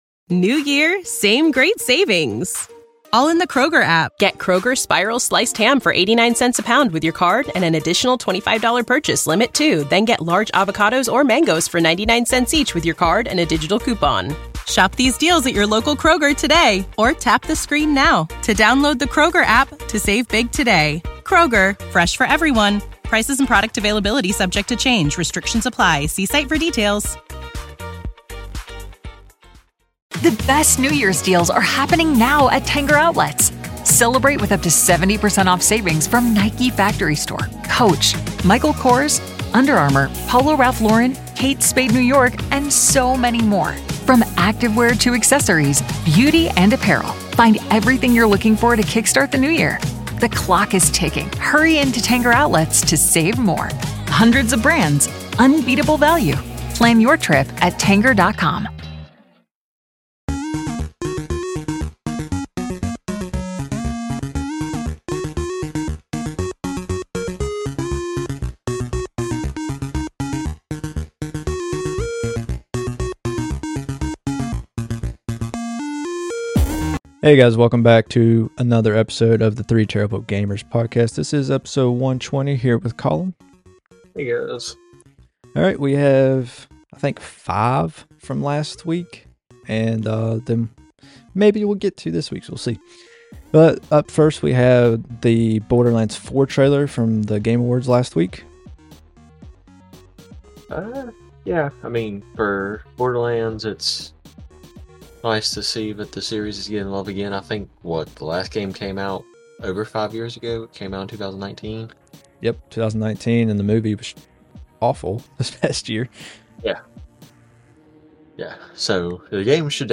Just a few guys discussing hot gaming topics, news, and rumors across a variety of consoles.